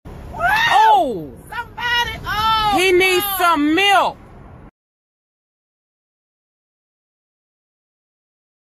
He needs some milk (funny Sound Effect For Comedy) 🤣 Download mp3 below…